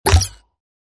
SeedlingHit2.ogg